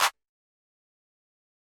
Claps
The Hills Clap.wav